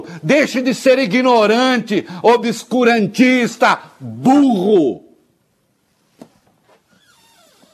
Reinaldo Azevedo falando sobre o Senador Eduardo Girão ter falado que a Coronavac tem fetos abortados. 2021